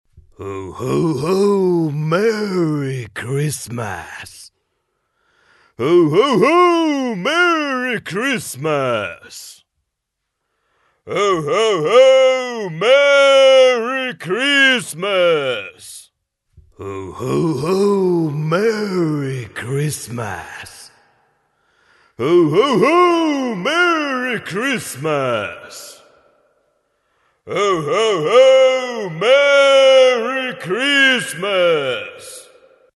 Santa's Voice Wishes You Merry Christmas with a Cheerful Ho Ho Ho